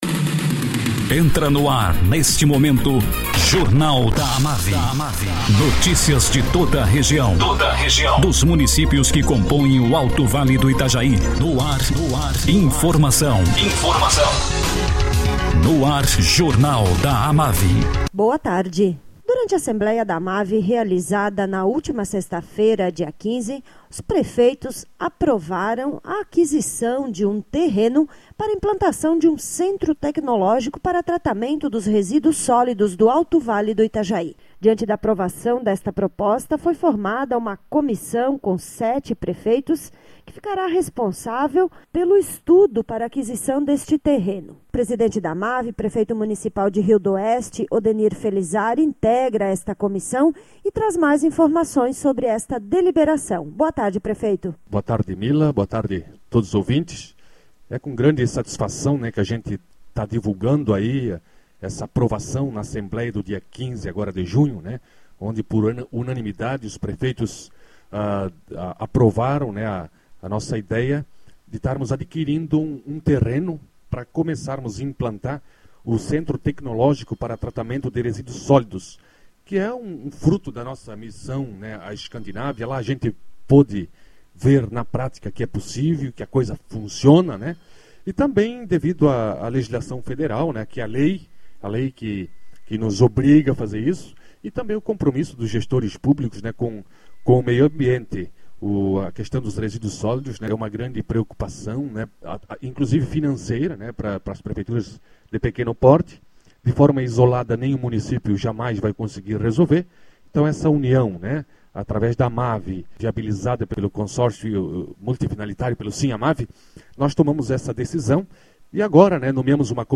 Presidente da AMAVI, prefeitio Odenir Felizari, fala sobre a aprovação da assembleia para aquisição de um terreno para implantação de um centro tecnológico para tratamento de resíduos sólidos no Alto Vale do Itajaí